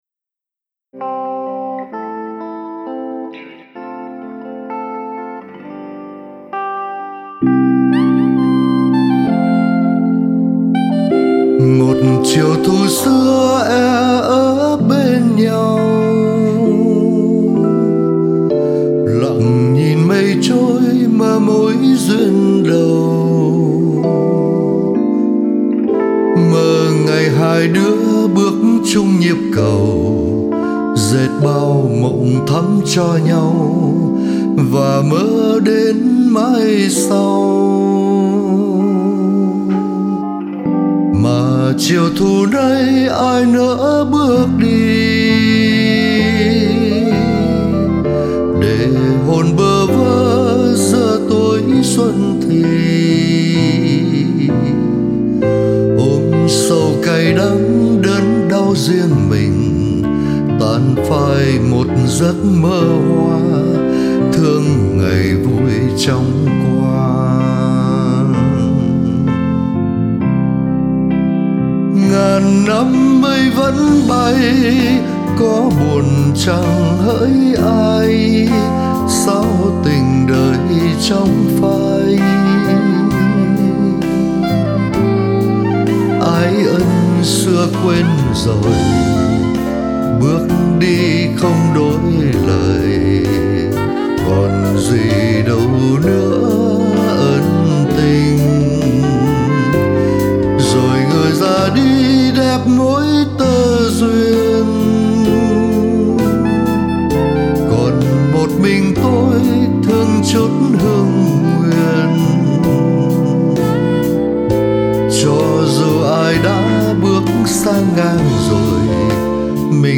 Hoà âm khá lạ tai.